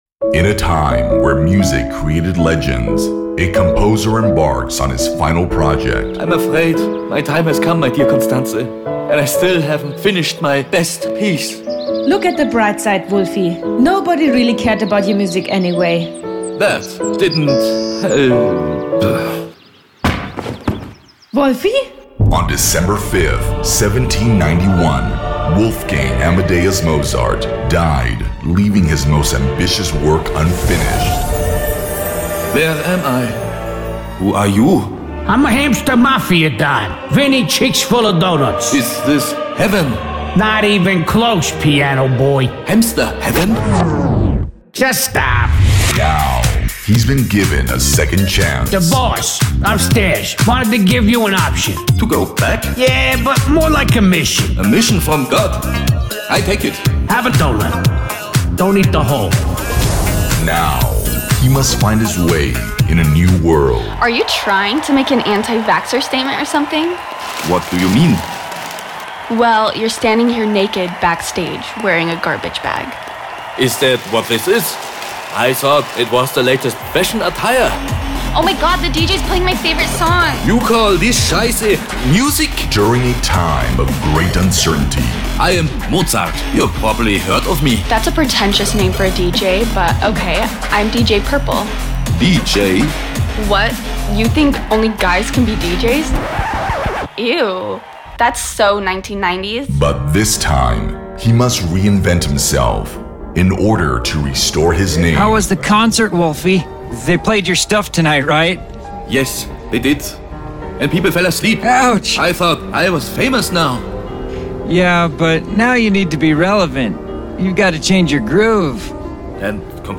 Deep, Cool, Commercial, Natural, Warm